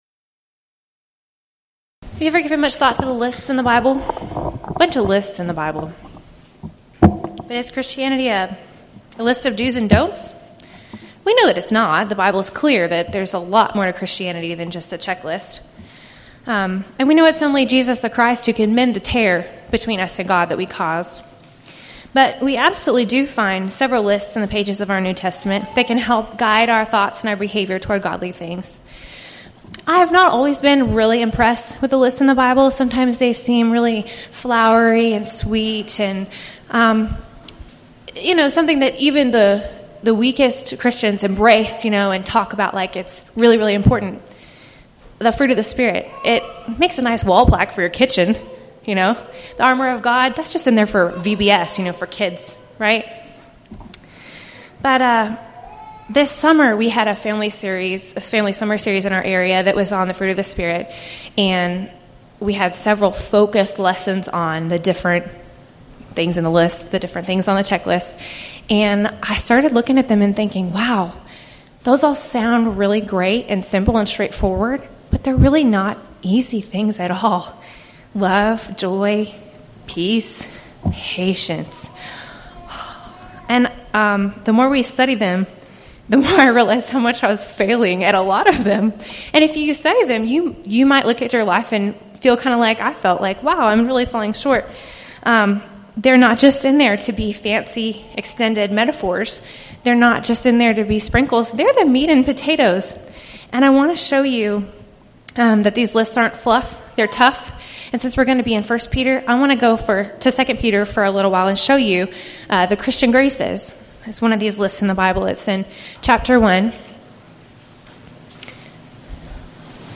Event: 3rd Annual Texas Ladies in Christ Retreat Theme/Title: Studies in I Peter